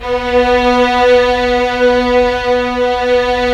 Index of /90_sSampleCDs/Roland L-CD702/VOL-1/STR_Vlns 1 Symph/STR_Vls1 Symph